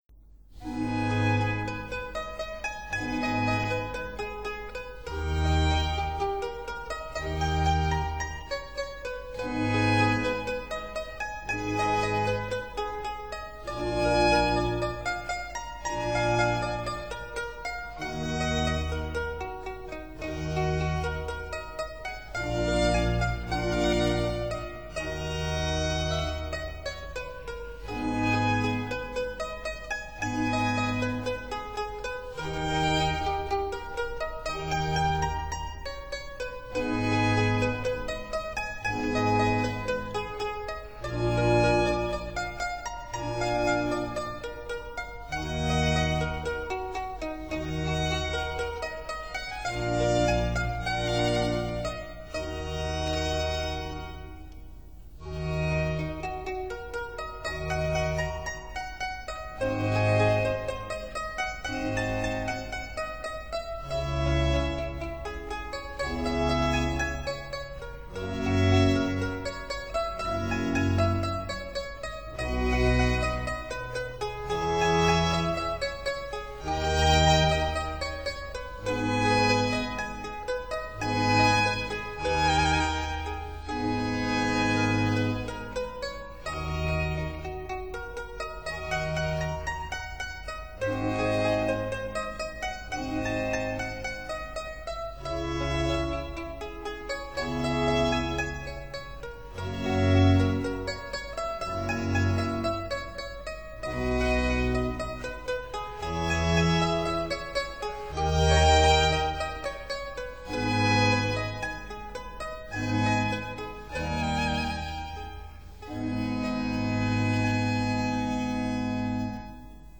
所屬時期/樂派： 巴洛克威尼斯樂派
Violin & Viola d'Amore